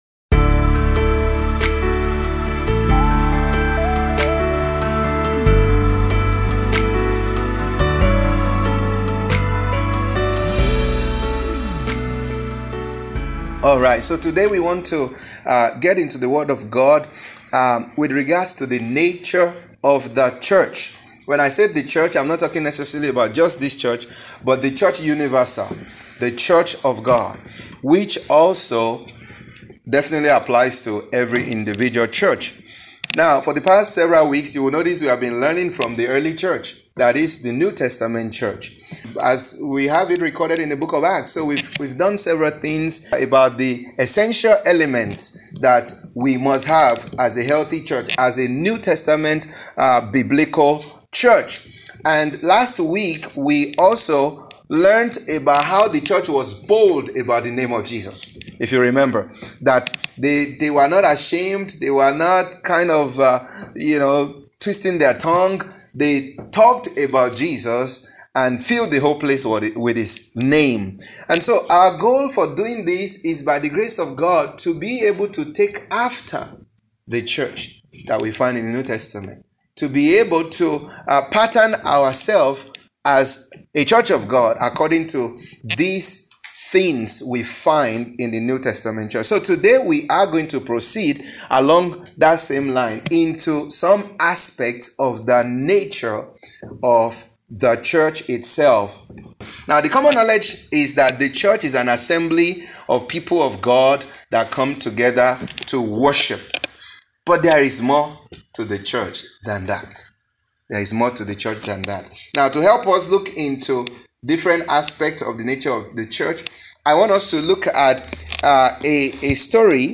This sermon sheds light on the nature of the church, which every believer must understand.